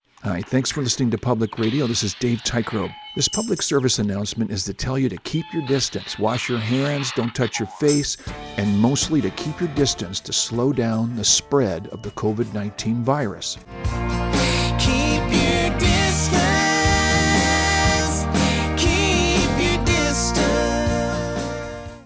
Recording Location: Gabriola Island
Type: PSA